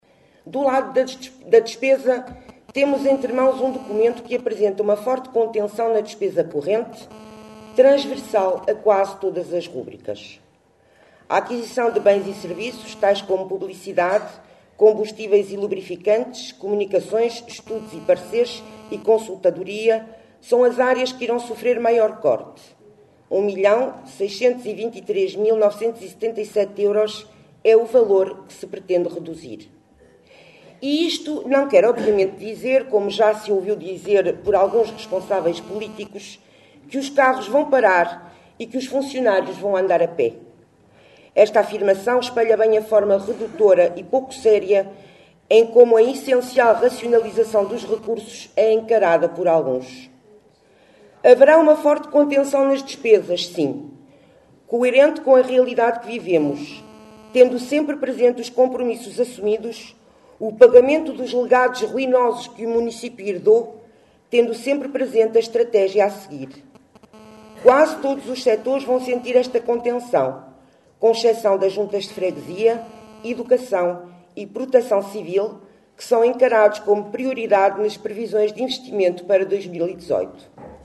Extratos da última reunião extraordinária da Assembleia Municipal de Caminha que decorreu na passada sexta-feira Teatro Valadares.